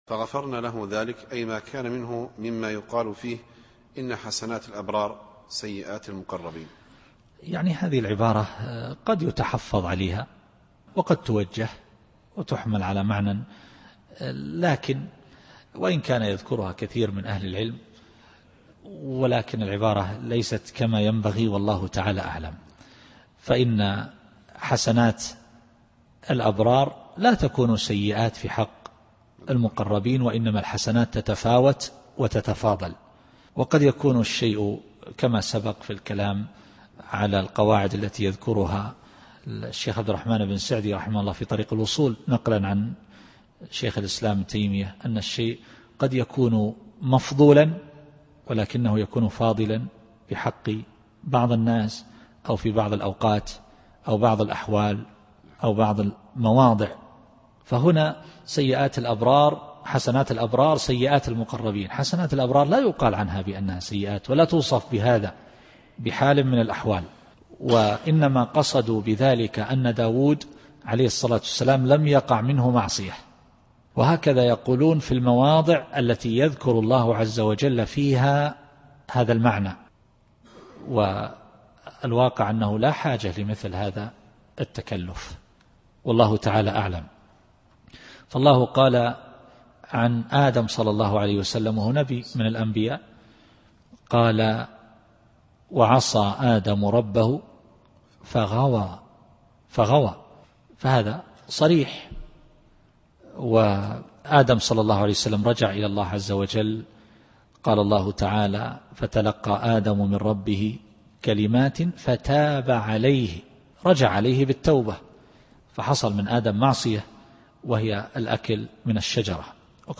التفسير الصوتي [ص / 25]